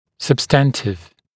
[səb’stæntɪv][сэб’стэнтив]существенный, по существу